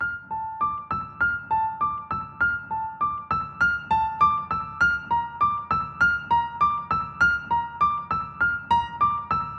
原声钢琴13100bpm
描述：B小调的HipHop/Rap钢琴循环曲。